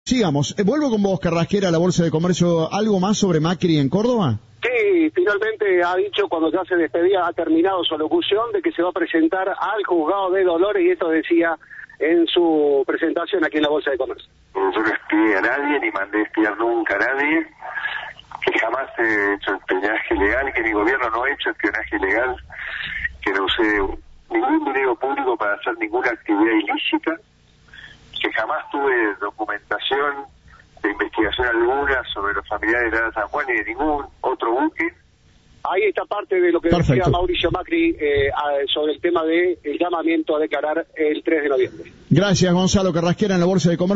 Fue en el marco de su disertación en la Bolsa de Comercio de Córdoba.
Informe